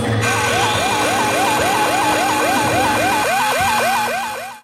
Craft alarm Free sound effects and audio clips
DISTANT SHIP ALARM.wav
WAILING_ALARM_uey.wav